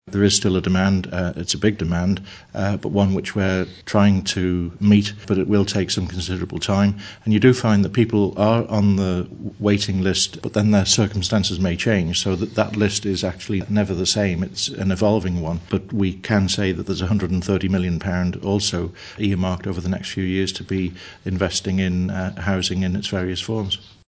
Social Care Minister Martyn Quayle told 3FM about the ambitious £130 million programme put in place by the Government, designed to help ease the backlog gradually over the next 5 years.